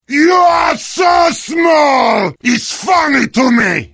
heavy-taunt-2.mp3